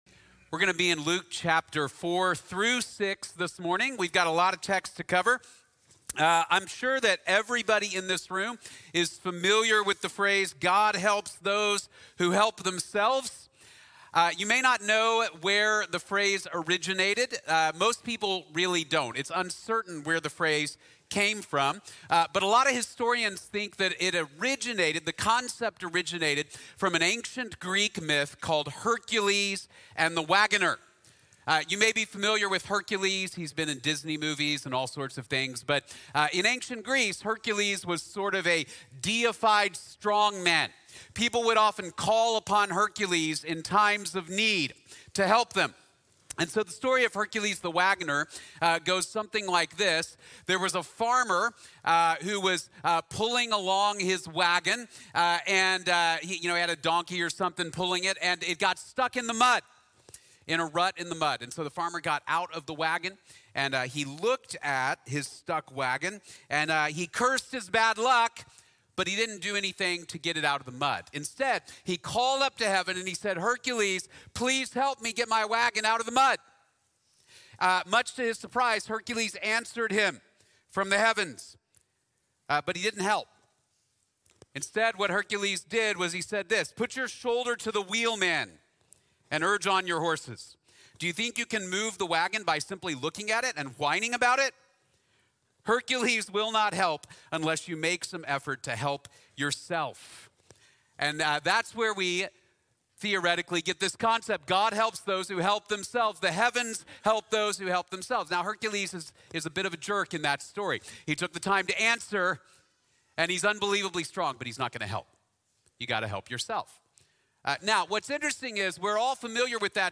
God Helps Those Who Cannot Help Themselves | Sermon | Grace Bible Church